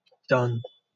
wymowa:
IPA/ˈdʌn/, X-SAMPA: /"dVn/